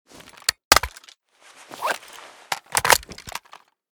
ace21_reload.ogg